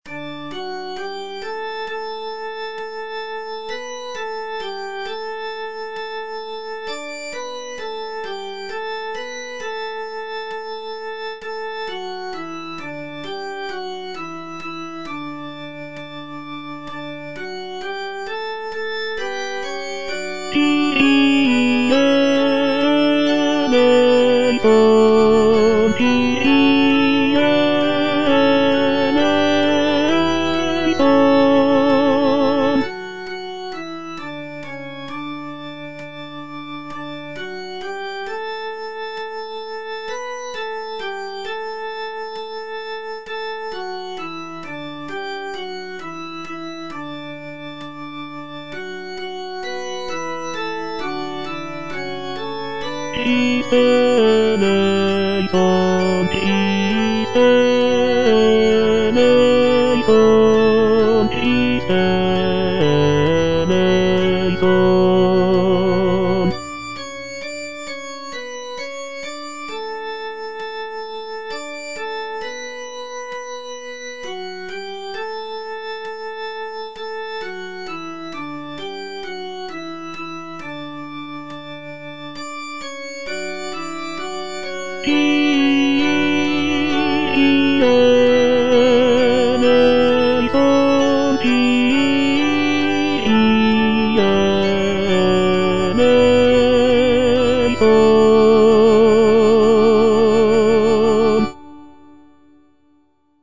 TENORS